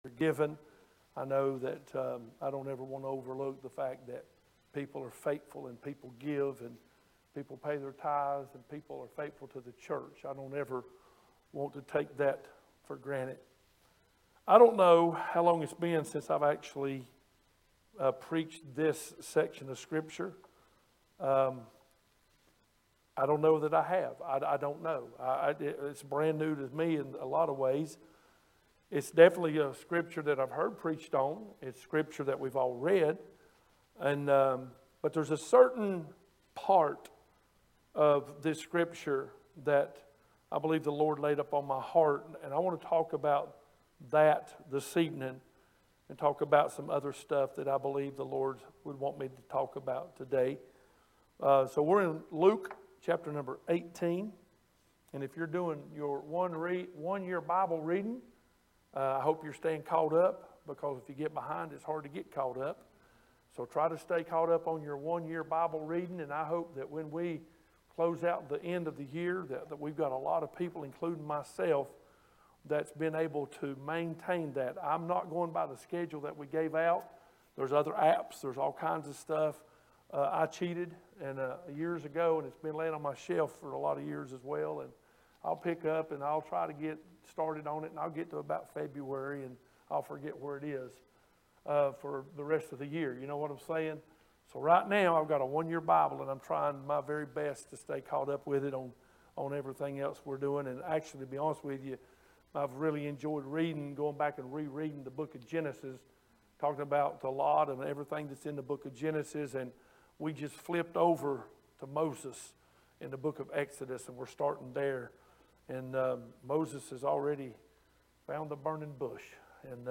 Sermons | Thomasville Church of God